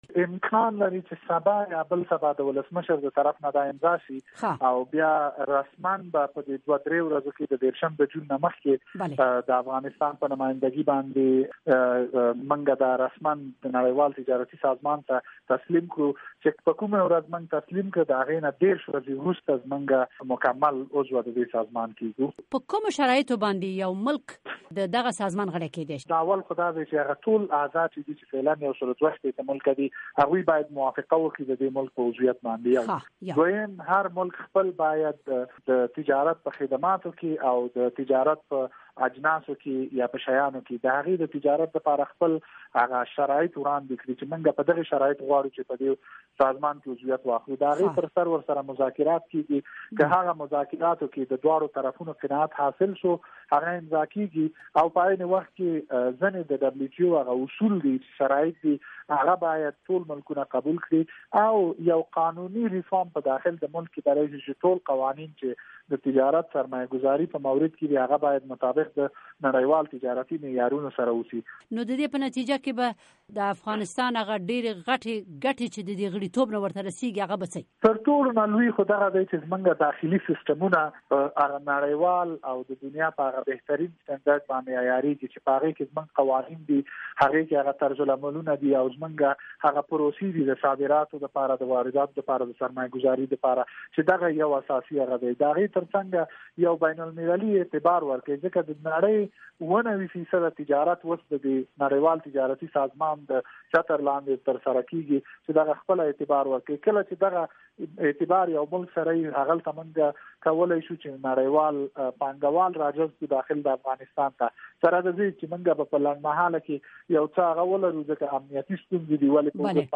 مرکې
د افغانستان د تجارت او صنایعو د وزارت معین مزمل شینواري مرکه